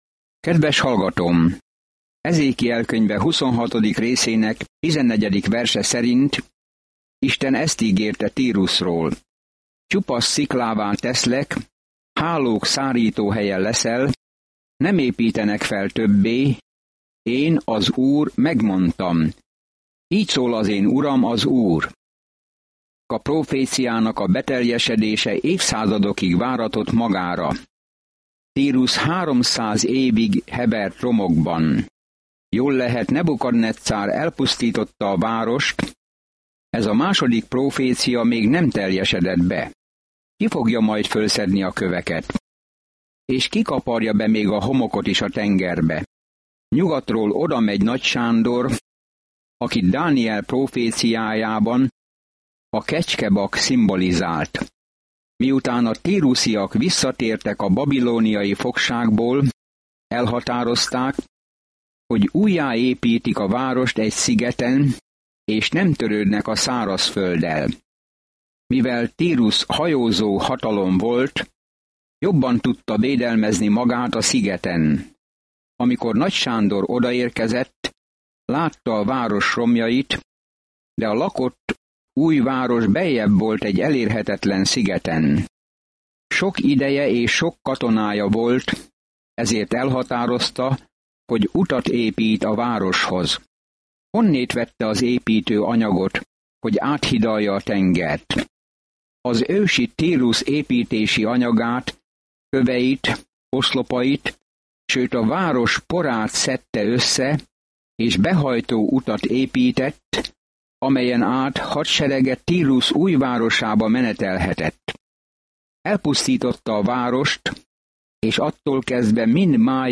Szentírás Ezékiel 27 Ezékiel 28:1-12 Nap 15 Olvasóterv elkezdése Nap 17 A tervről Az emberek nem hallgattak Ezékiel figyelmeztető szavaira, hogy térjenek vissza Istenhez, ezért ehelyett az apokaliptikus példázatokat adta elő, és ez meghasította az emberek szívét. Napi utazás Ezékielben, miközben hallgatja a hangos tanulmányt, és olvassa kiválasztott verseket Isten szavából.